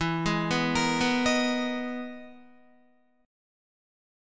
EM#11 chord